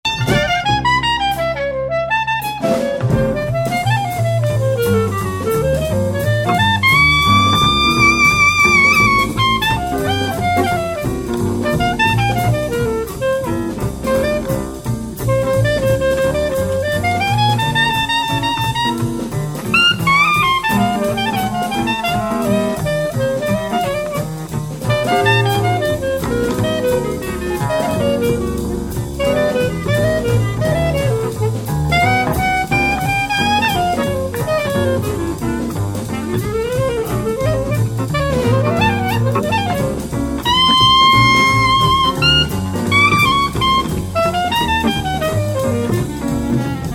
Strings 9